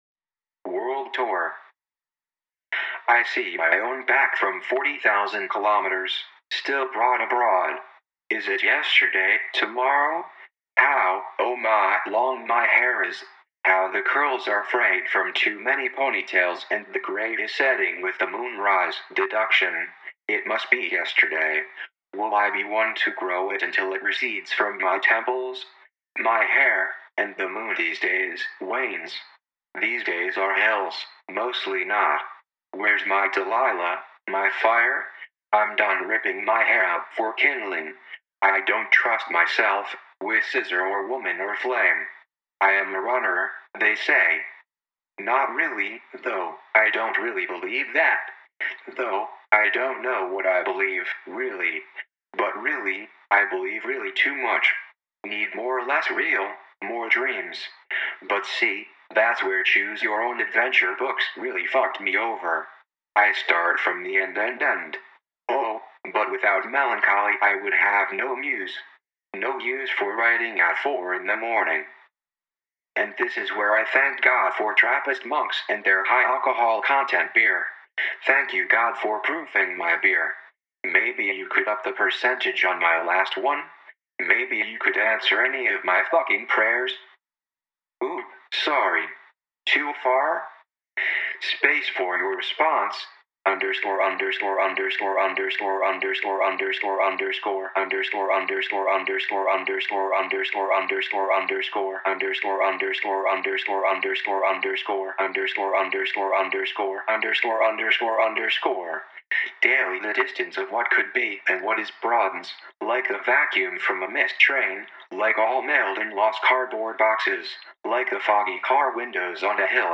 *live reading*